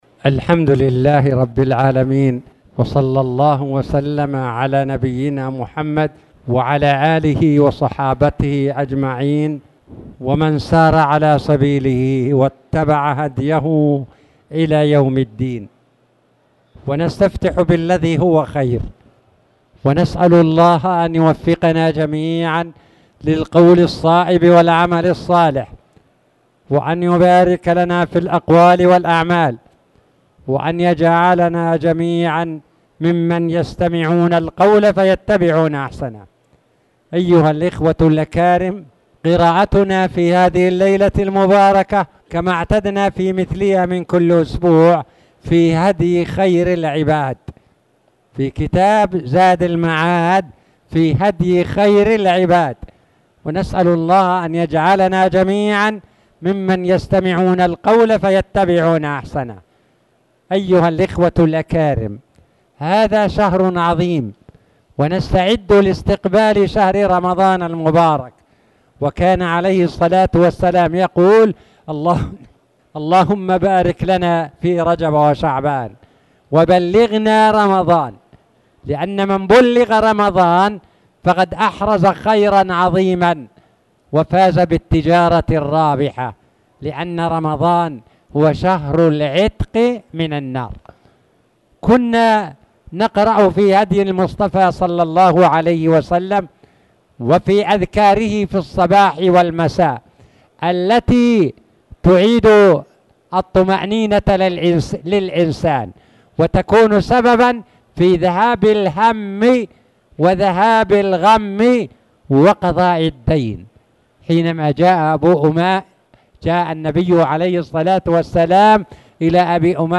تاريخ النشر ٢٢ شعبان ١٤٣٨ هـ المكان: المسجد الحرام الشيخ